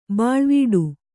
♪ bāḷvīḍu